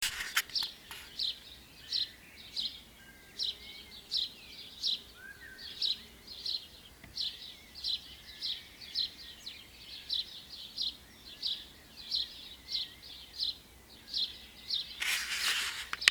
A digital dictation machine was borrowed well in advance (couldn’t think how else to record it as my phone is from the dark ages) and a few practice runs attempted – not at ridiculous o’clock in the morning of course, but in more civilised early evenings.
Once the blackbird had got going, more birds seemed to join in, although I couldn’t identify anything specific from the mix.
Of course the main thing  you can still hear over-riding everything is the blackbird.
About 04:30 the bottom of the garden was filled with lots of cheaping voices – the sparrows had woken up.
0439sparrows.mp3